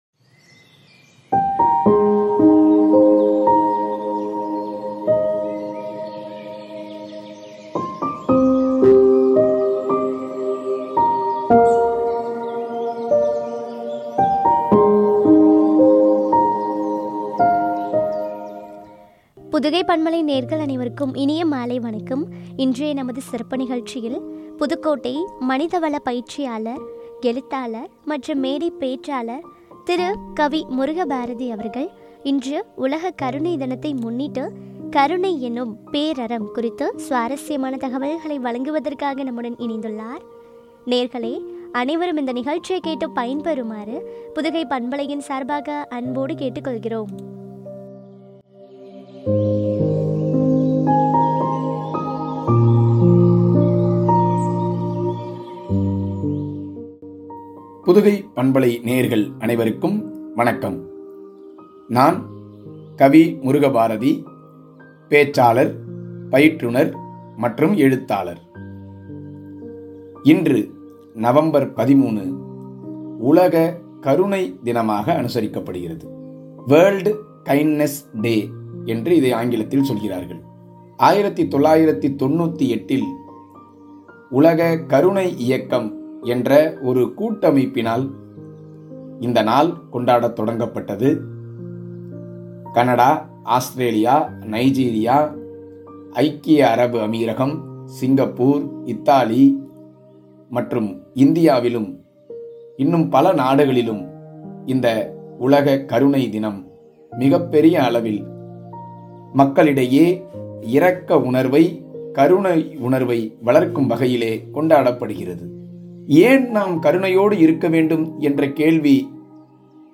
” “கருணை எனும் பேரறம்” வழங்கிய உரையாடல்.